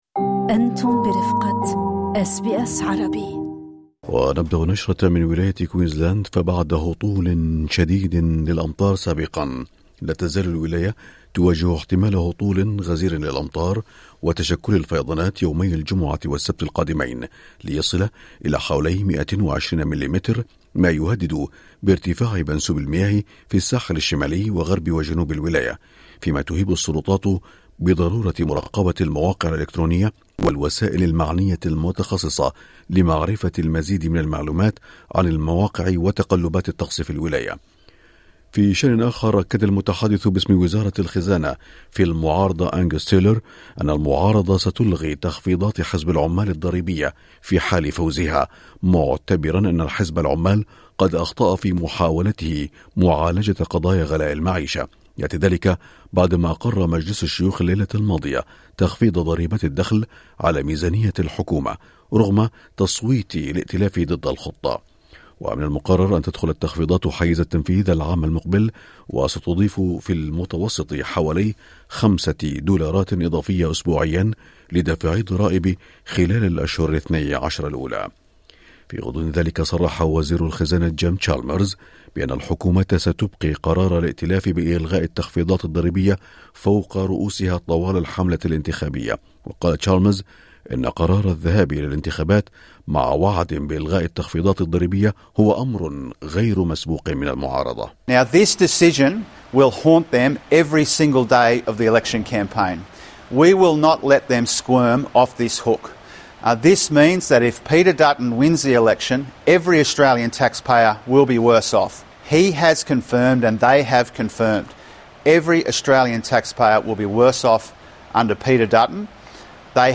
نشرة الظهيرة 27/3/2025